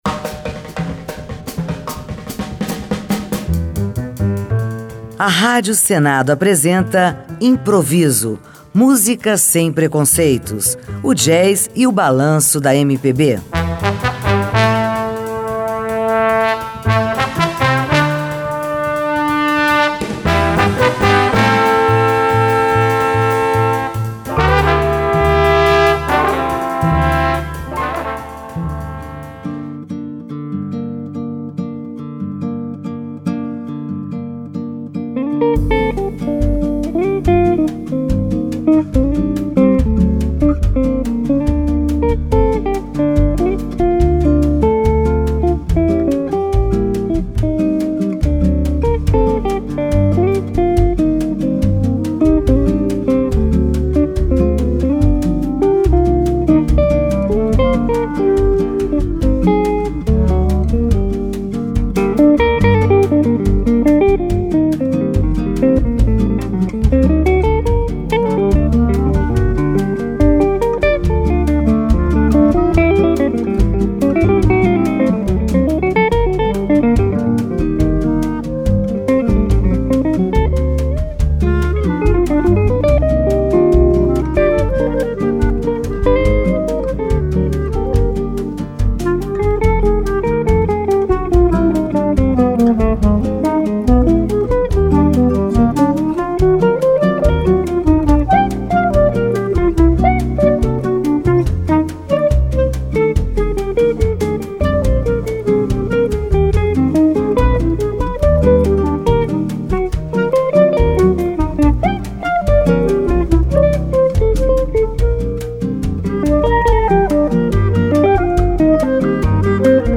Choro Jazz
Nesta edição, você vai ouvir o que o jazz brasileiro fez com o choro, em uma seleção muito especial de choro jazz.